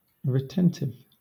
Ääntäminen
Southern England
IPA : /ɹɪˈtɛntɪv/